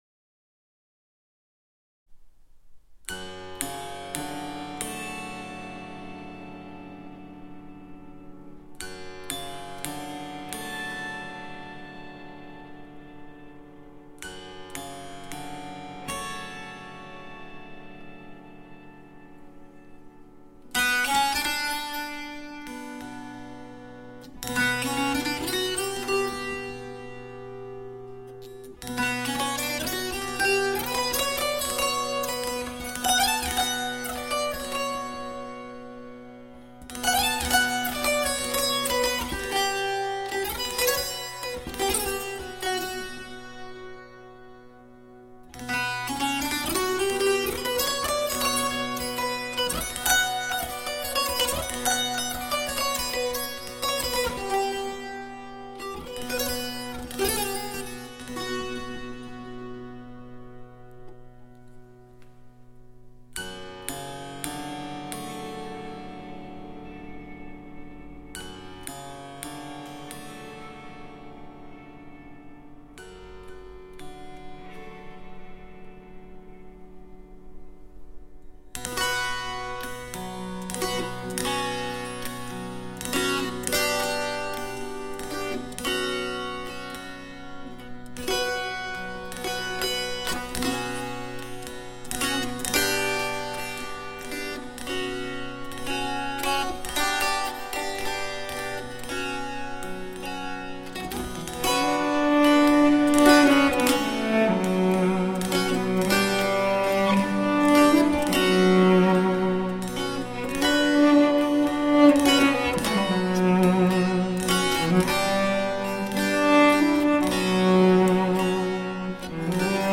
Enregistré à l'église Sint-Pieter de Gooik, 2021
Avec un jeu au bâton ou aux doigts.